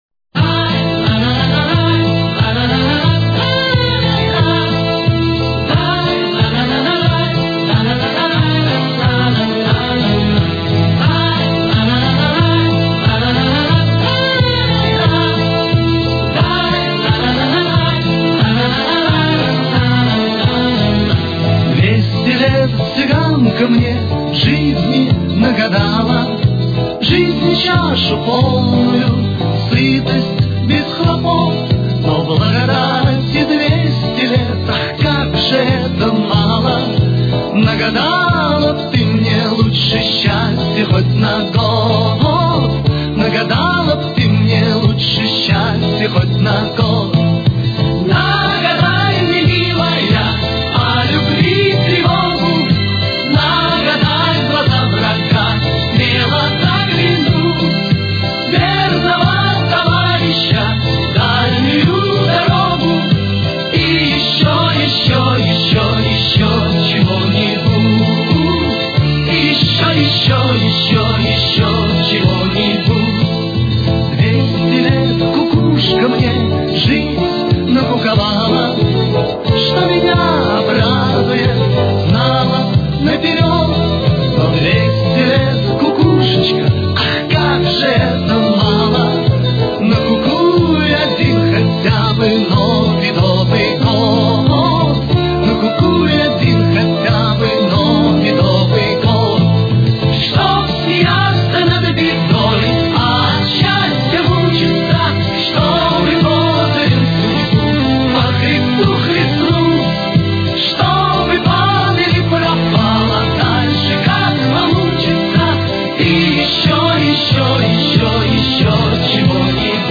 Ля минор. Темп: 94.